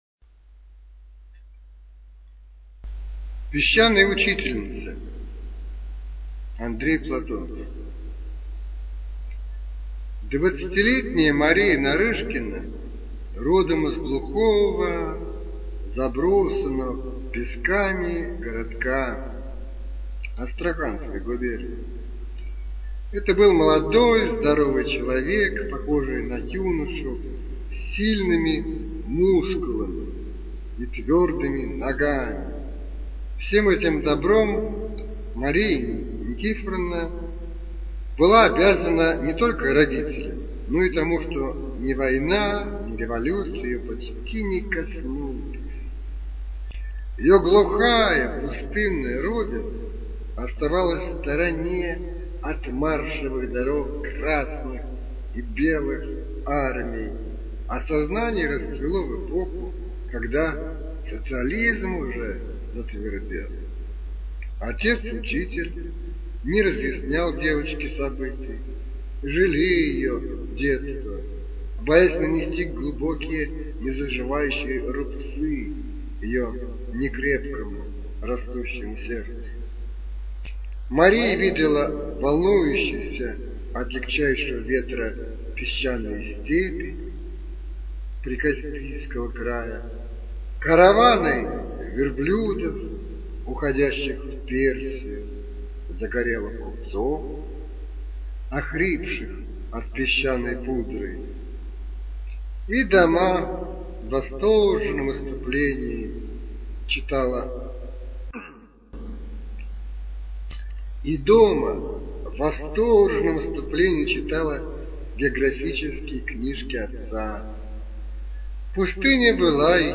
Песчаная учительница - аудио рассказ Платонова А.П. Рассказ про молодую учительницу, которая по распределению попала в глухое село Хошутово.